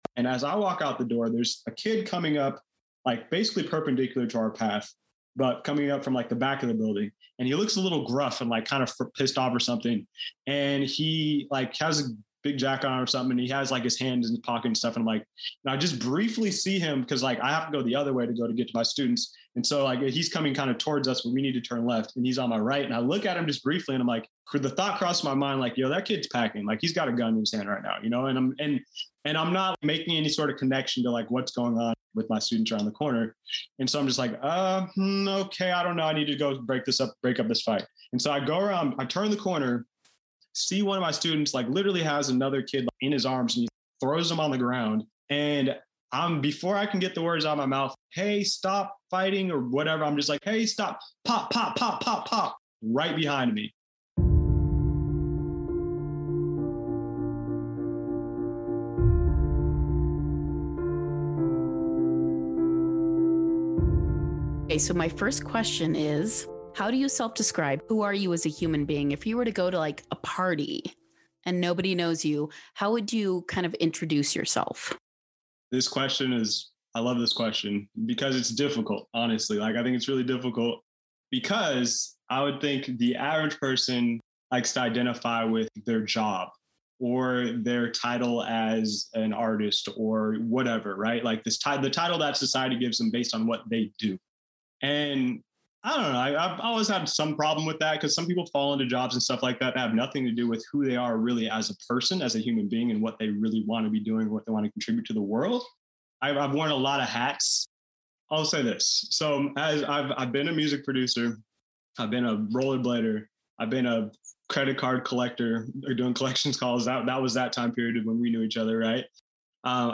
at least that what it felt like during our Zoom interview